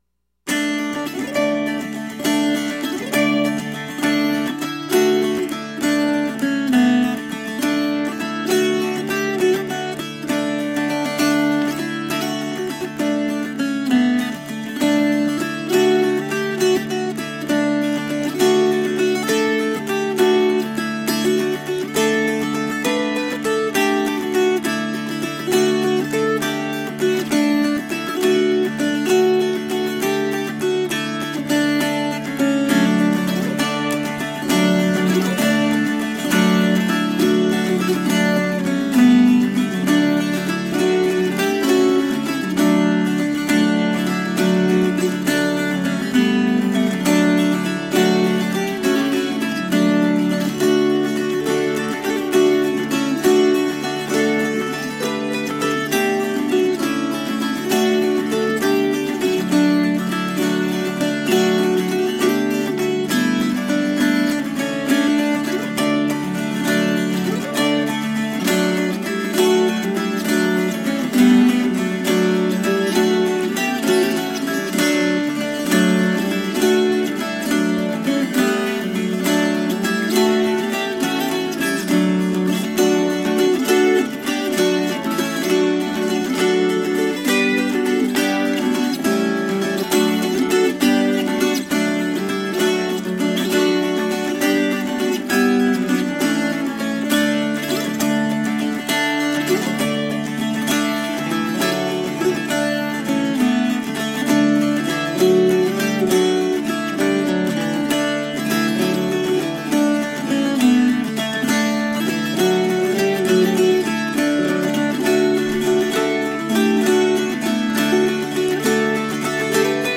Smile-inducing, toe-tapping folkgrass.